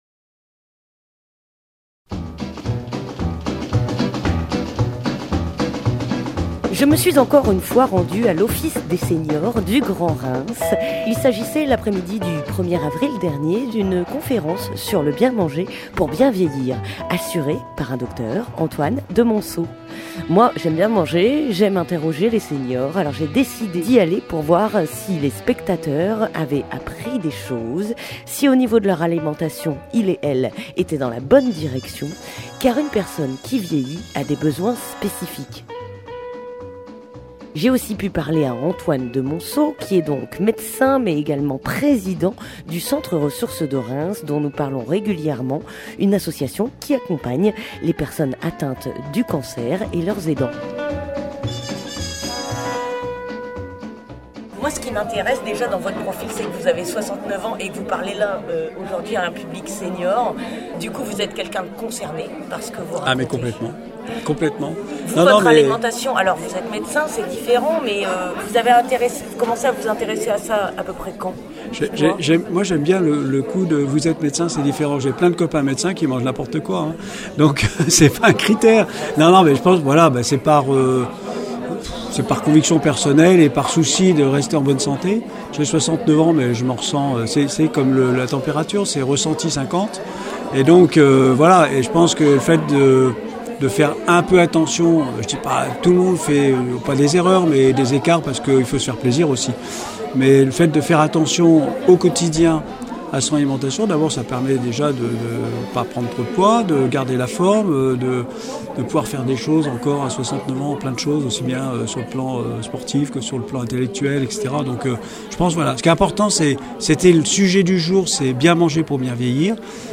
Reportage à l'ORRPA. (16:23)